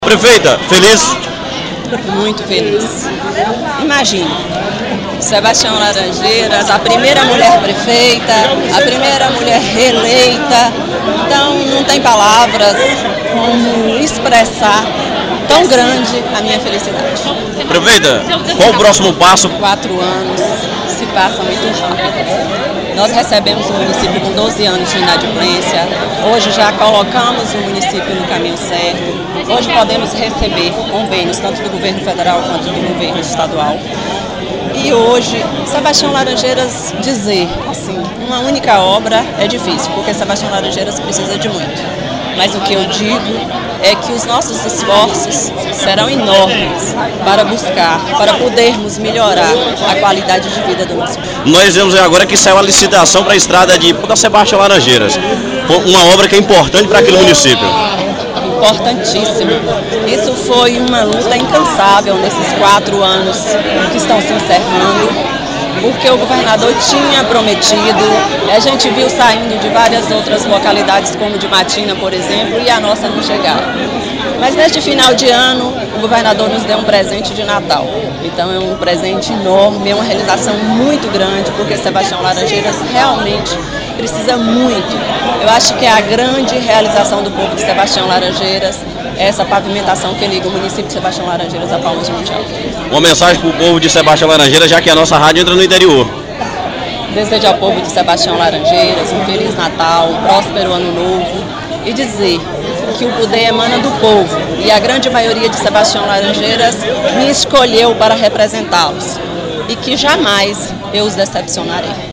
O ato solene aconteceu na tarde desta quinta-feira, 13 de dezembro.